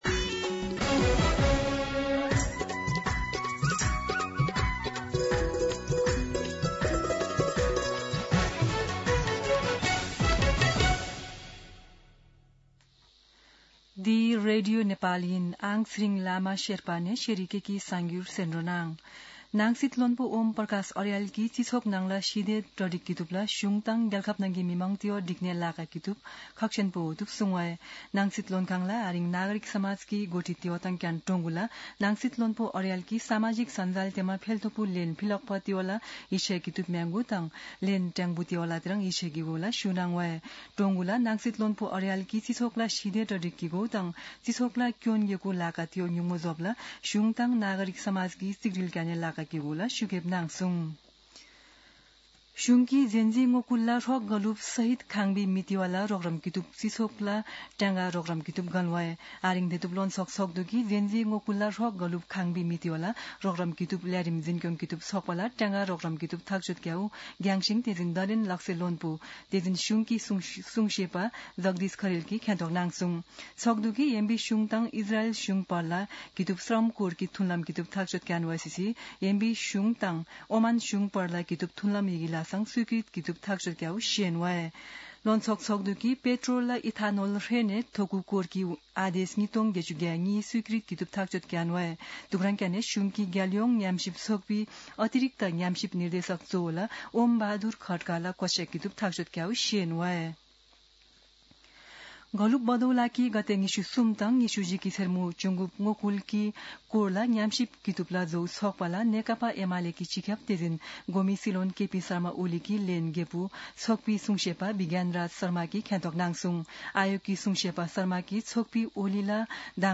शेर्पा भाषाको समाचार : २१ पुष , २०८२
Sherpa-News-21.mp3